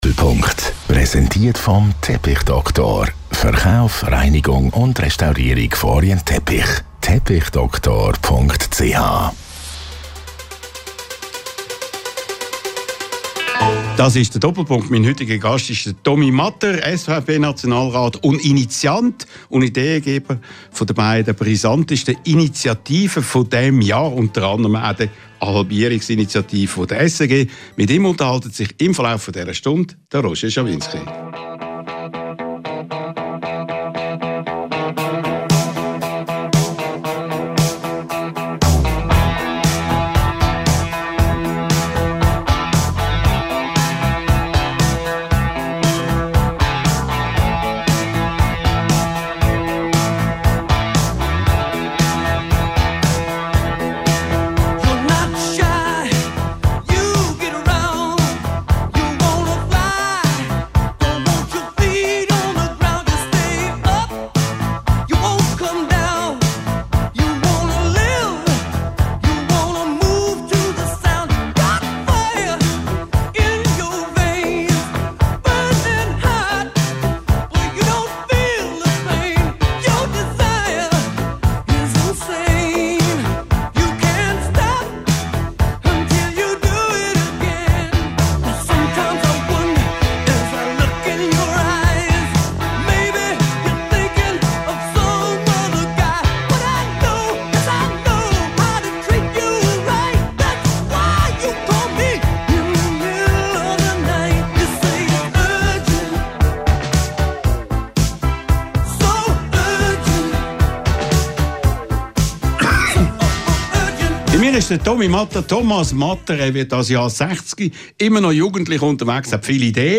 Diese Woche ist SVP-Nationalrat Thomas Matter zu Gast bei Radio1-Chef Roger Schawinski. In diesem Jahr wird über die Halbierungs (SRG)- und 10- Millioneninitiative abgestimmt.